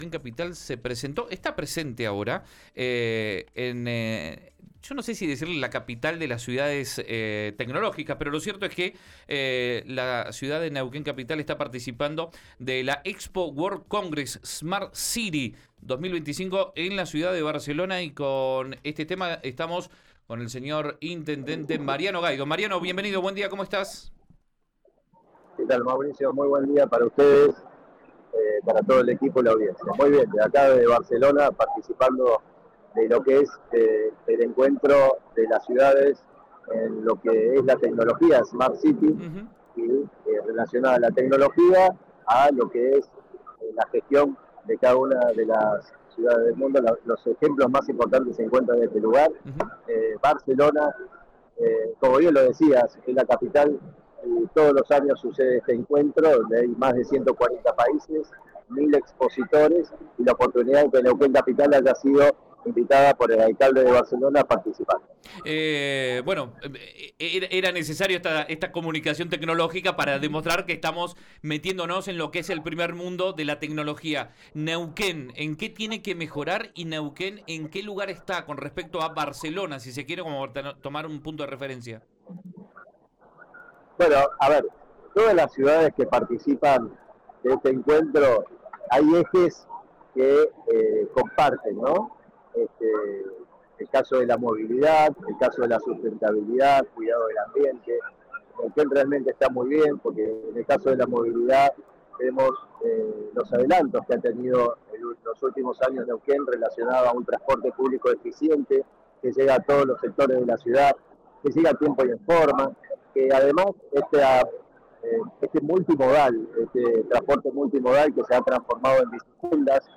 En diálogo con Río Negro Radio, Gaido explicó que la nueva tecnología, que ya está siendo implementada para modernizar trámites municipales, utilizará datos en tiempo real sobre el fluido vehicular, provistos por herramientas como Google, para ajustar la sincronización de los semáforos.